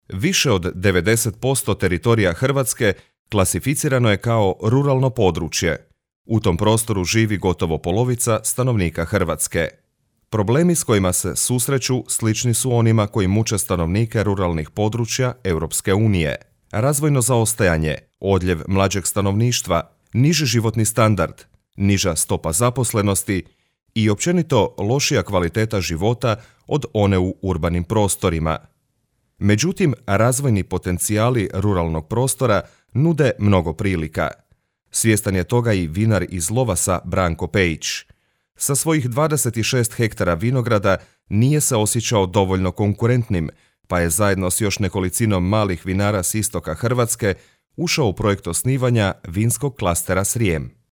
Hırvatça Seslendirme
Erkek Ses